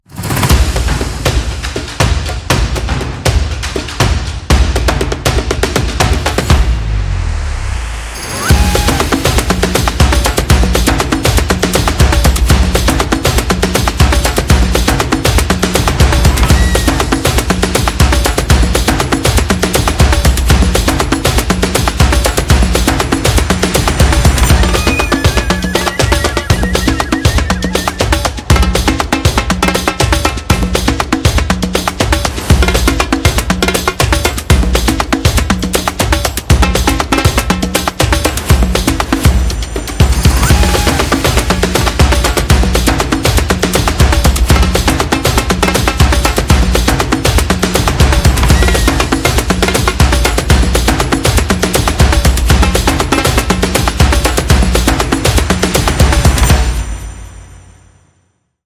Percussion World